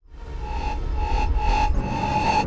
enemycome2.wav